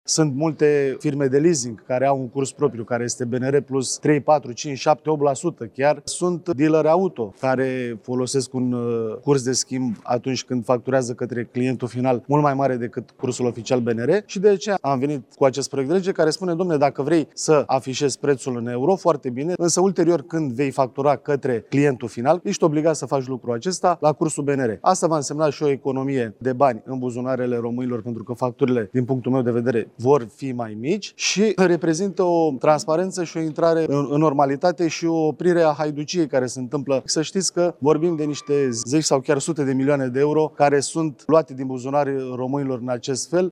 Cezar Drăgoescu, deputat USR: „Vorbim de niște zeci sau chiar sute de milioane de euro care sunt luate din buzunarele românilor în acest fel”